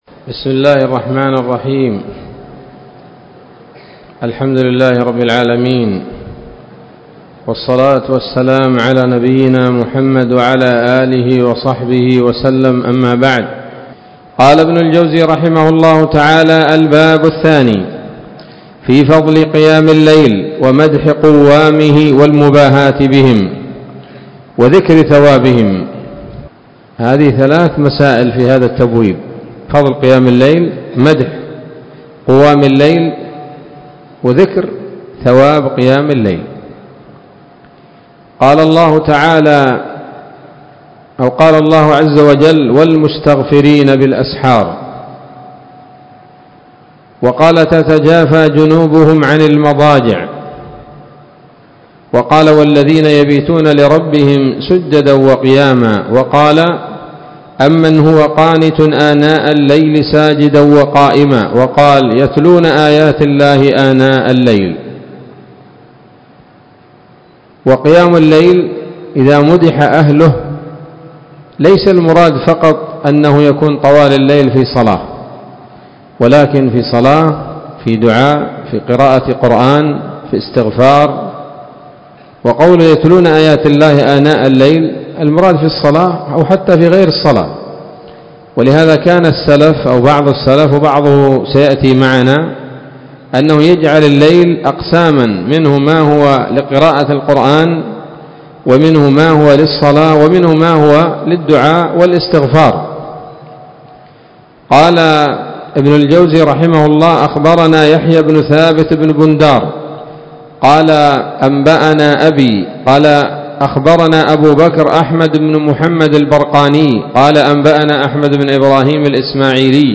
الدرس الثالث من كتاب "قيام الليل" لابن الجوزي رحمه الله تعالى